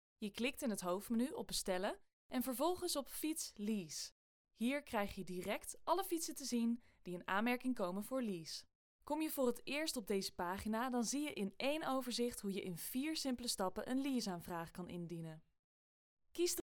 Jong, Natuurlijk, Speels, Toegankelijk, Vriendelijk
E-learning
Equipped with a professional home studio, she delivers high-quality recordings with a fast turnaround, ensuring both efficiency and excellence in every project.